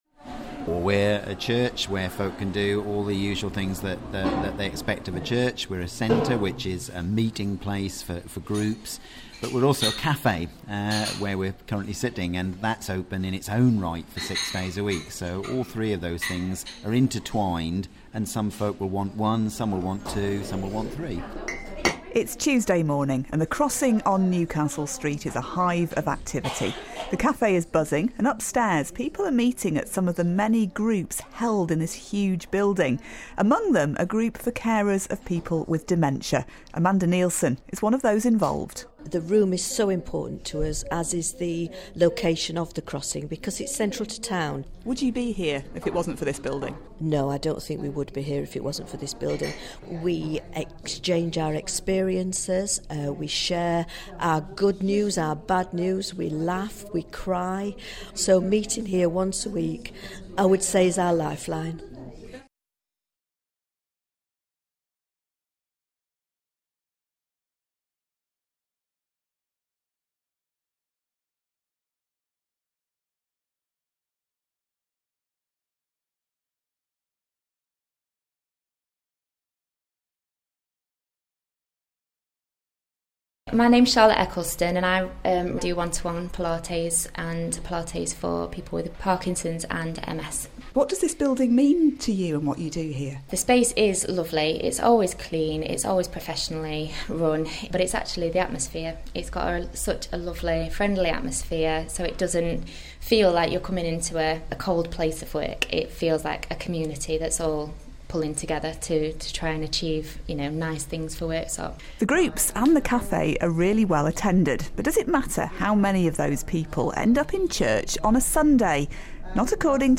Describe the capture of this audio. As broadcast on BBC Radio Sheffield's Sunday Breakfast shows, 22nd May 2016.